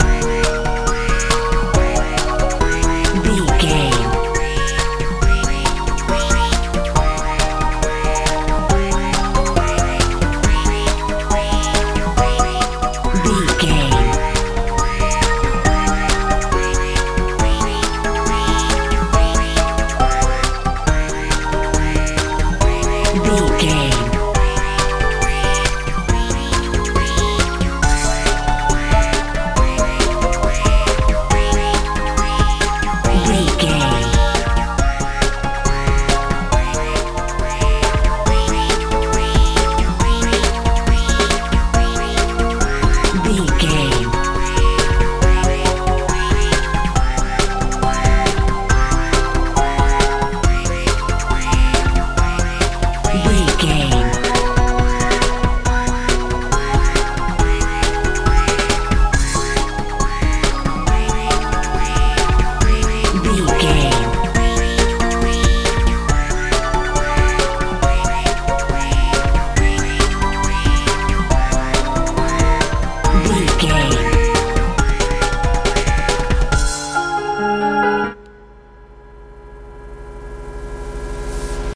bubblegum pop
Aeolian/Minor
fun
playful